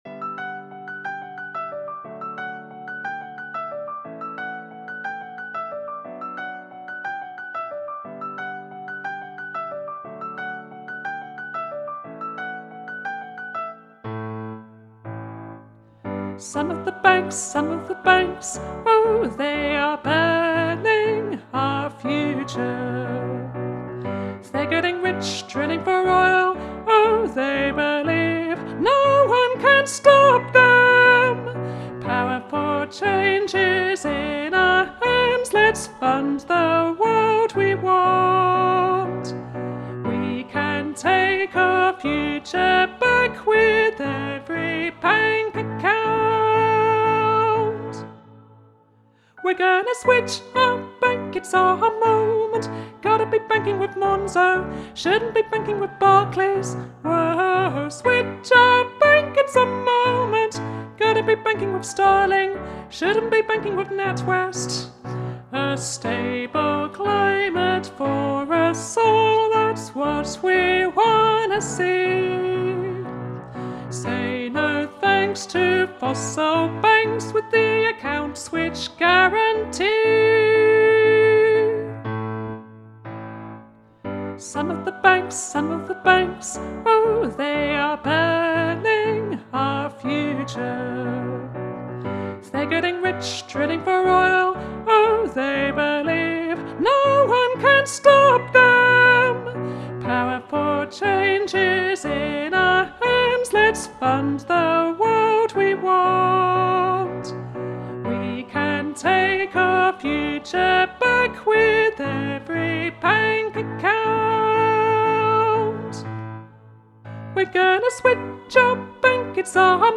Higher voices: